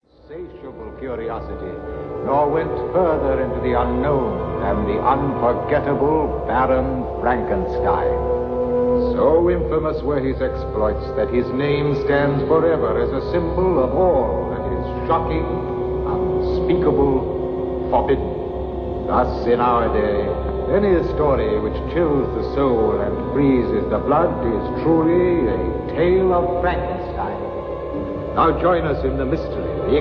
Halloween movie soundscape